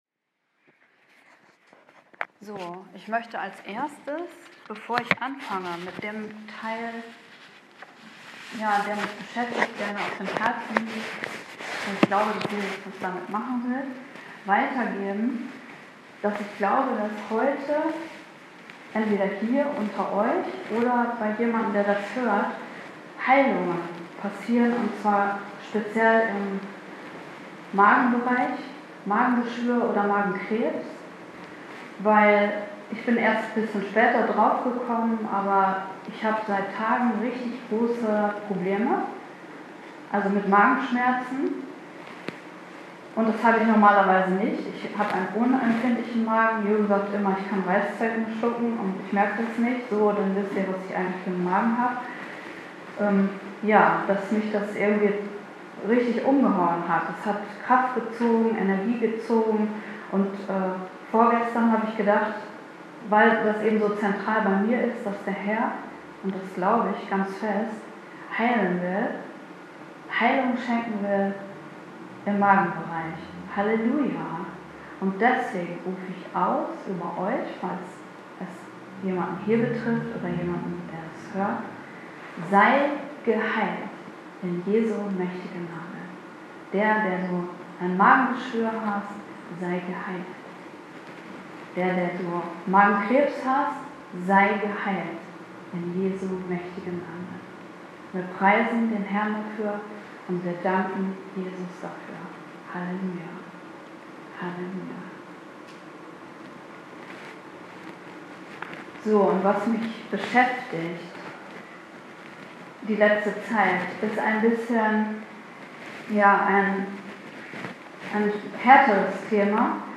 Live-Mitschnitt der Predigt bei Gott[ER]lebt vom 12. März 2016